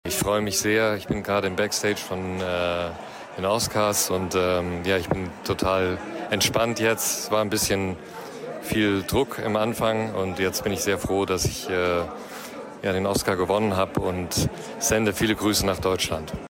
Volker Bertelmann hat uns eine Grußbotschaft geschickt.
stz---0313-oscar-bertelmann-gruss.mp3